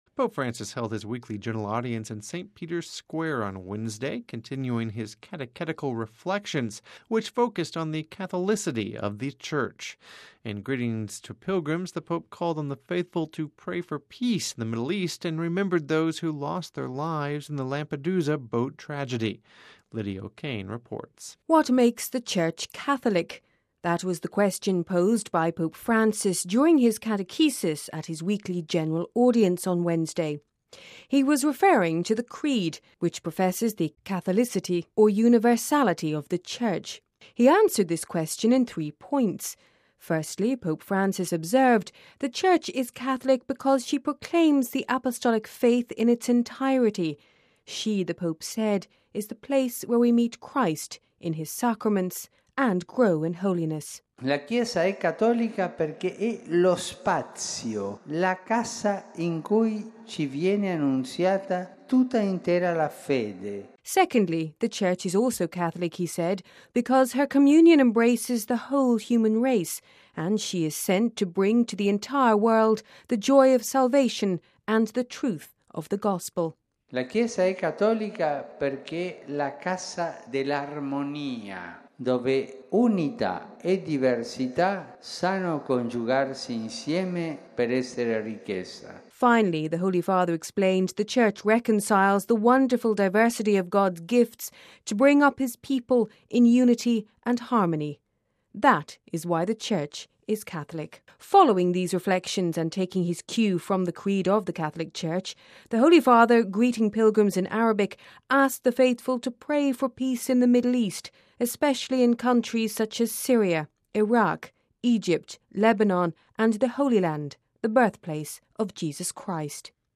(Vatican Radio) Pope Francis held his weekly General Audience in St Peter’s Square on Wednesday. The Holy Father continued his catechetical reflections , focusing on the catholicity of the Church. In greetings to pilgrims, the Pope called on the faithful to pray for peace in the Middle East and remembered those who lost their lives in the Lampedusa boat tragedy.